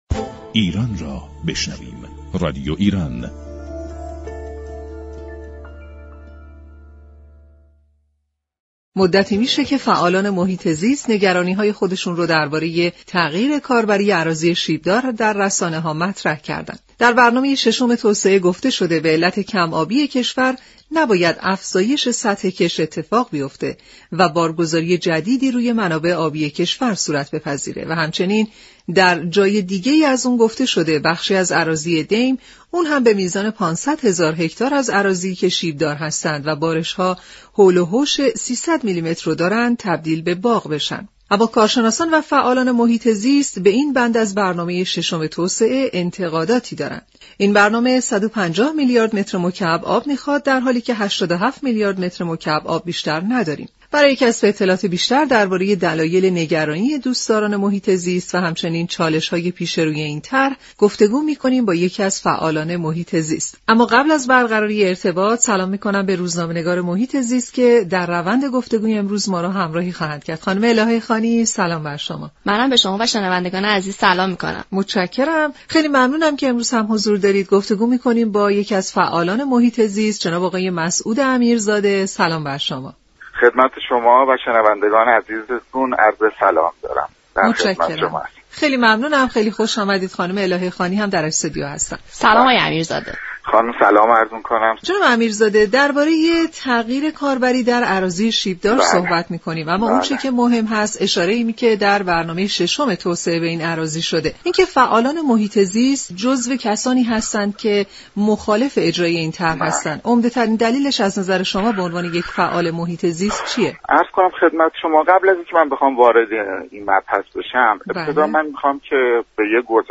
در گفت و گو با رادیو ایران